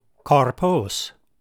καρπός / karpos